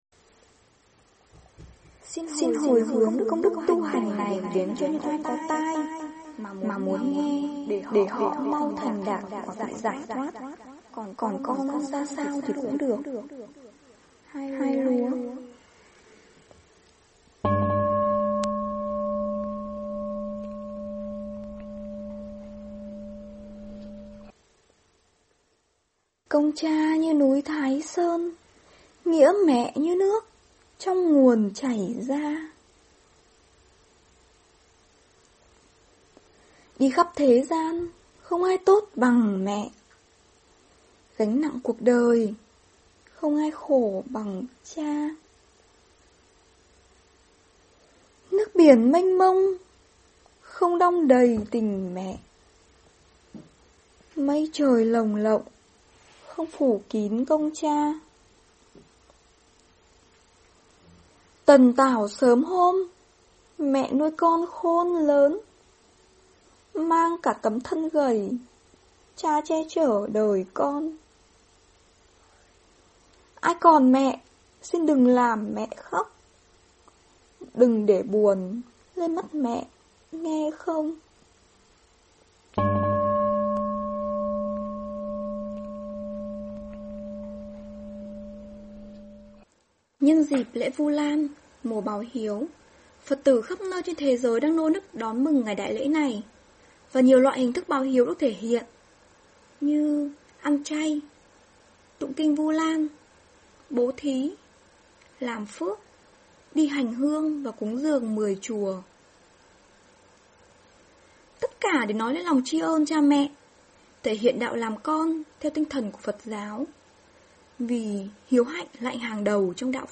08:39 AM Xin giới thiệu một giọng đọc trẻ và đầy nhiệt tình Vu Lan Mùa Báo Hiếu Cùng kính mời bà con ghé qua trang chủ để đọc lại bài